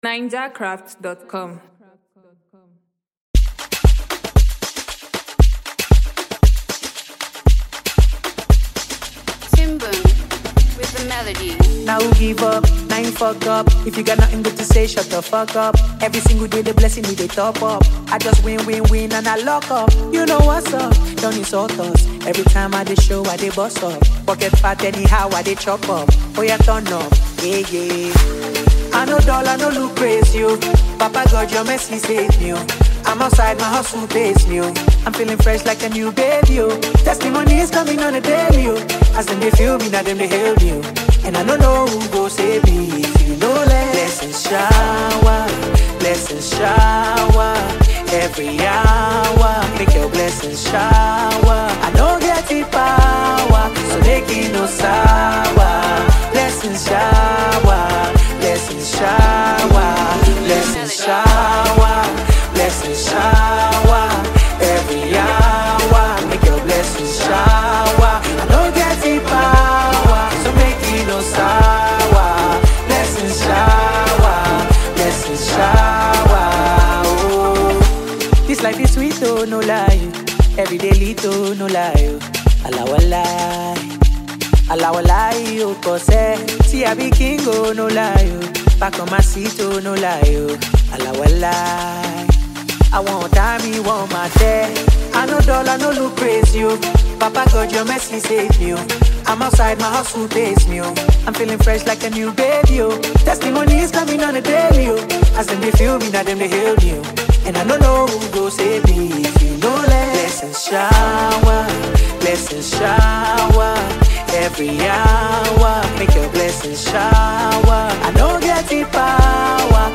Prominent Nigerian Singer